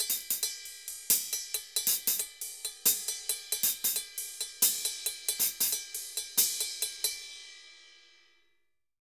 Ride_Merengue 136-2.wav